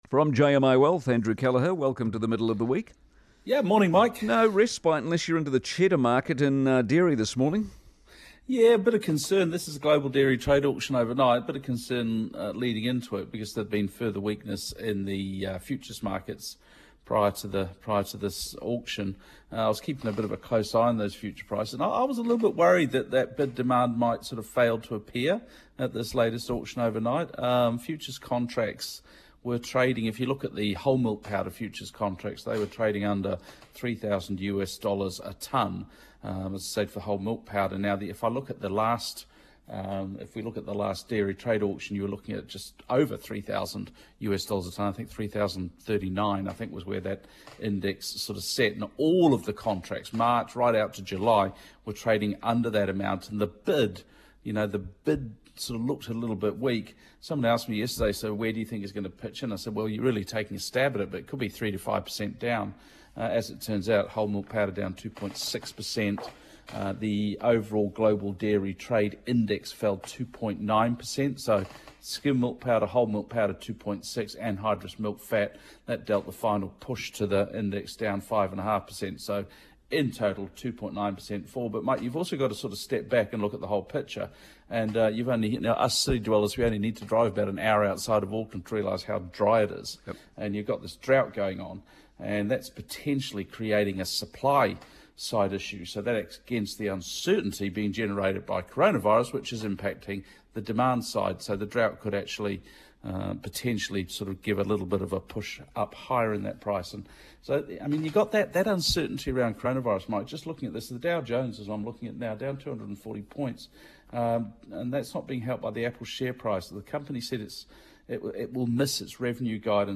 Newstalk ZB Commentary